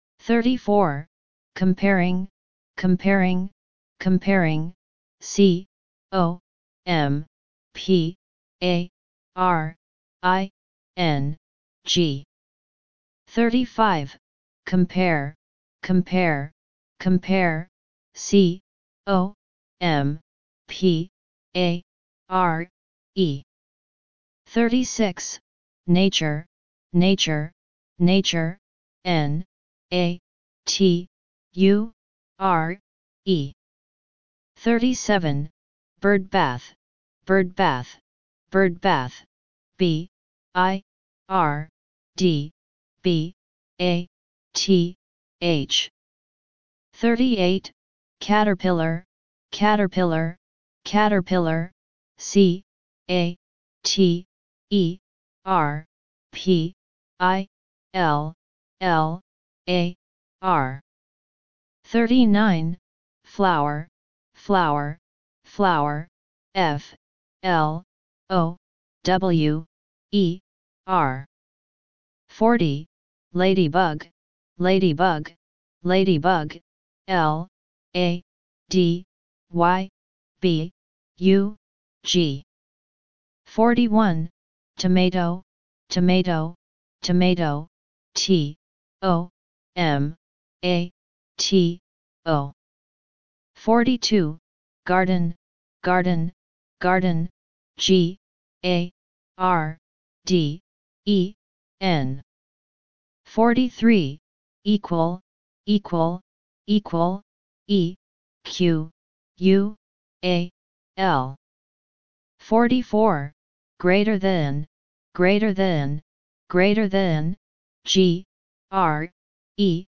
三下英語單字語音檔P3 （最近一週新上傳檔案）